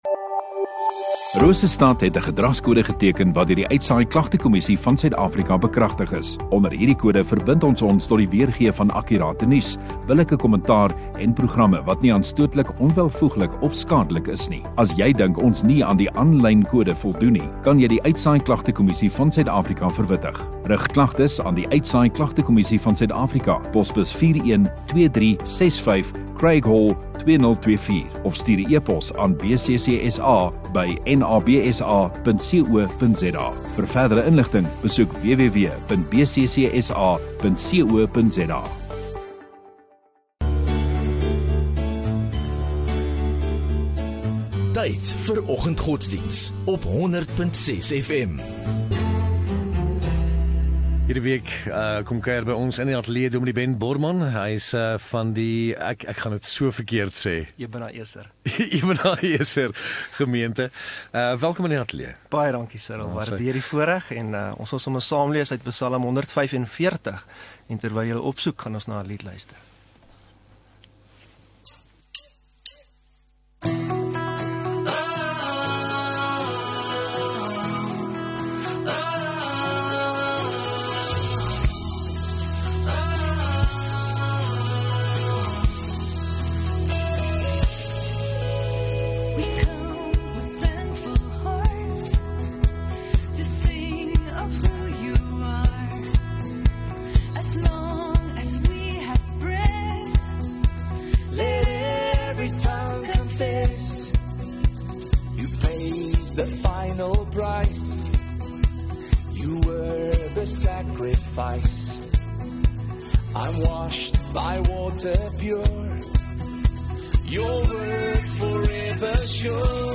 20 May Maandag Oggenddiens